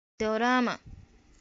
pronunciation) or Magajiya Daurama (pronunciation) (fl. 9th century) was a ruler of the Hausa people who, as the Last Kabara of Daura, presided over the upheaval that saw a transference of power from the matriarchal royal system.[1] Oral traditions remember her as the founding "queen grandmother" of the Hausa Empire started in the area known today as the monarchies of northern Niger and Nigeria.[2][3] The story of Magajiya Daurama is partially told in the legend of Bayajidda.[4]